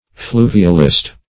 Search Result for " fluvialist" : The Collaborative International Dictionary of English v.0.48: Fluvialist \Flu"vi*al*ist\, n. One who exlpains geological phenomena by the action of streams.